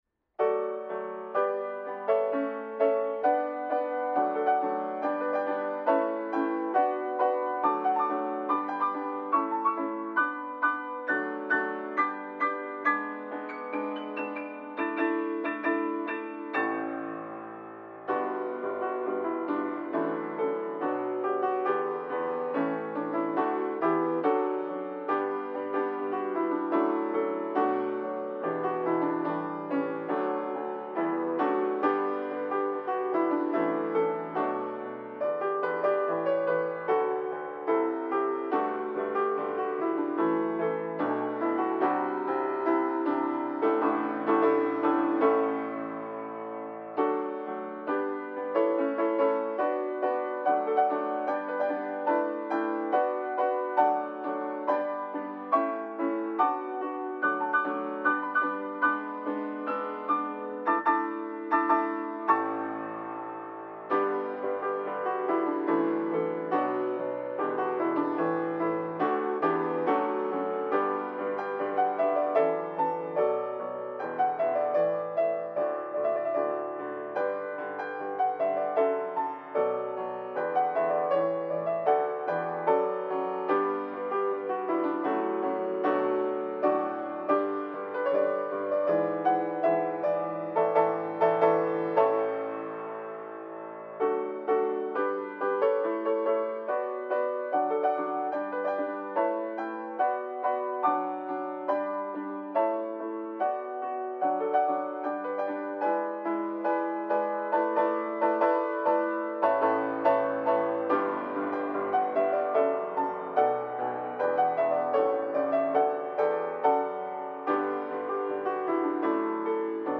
-生ピアノ（生演奏）
YouTuber、Vtuber向け、ライブ配信待機BGMです。